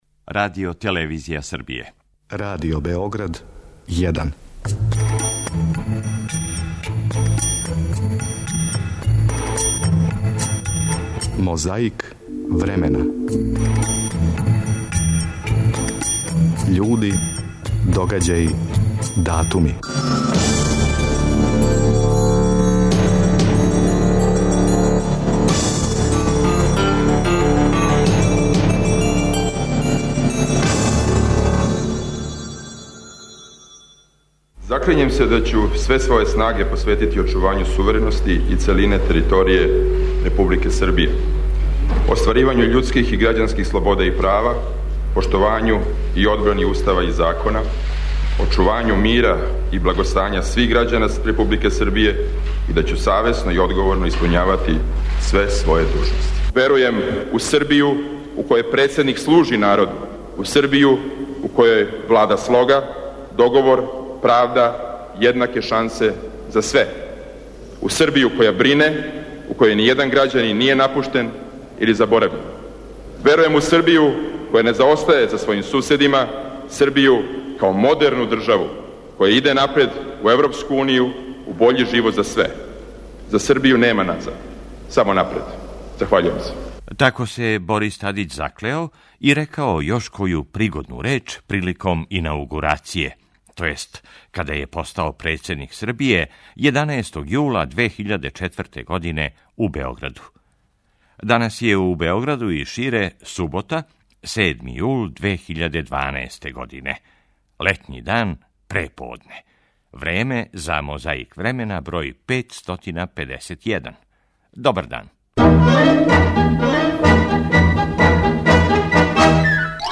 На почетку одлазимо на инаугурацију Бориса Тадића 11. јуна 2004. године...
Подсећа на прошлост (културну, историјску, политичку, спортску и сваку другу) уз помоћ материјала из Тонског архива, Документације и библиотеке Радио Београда.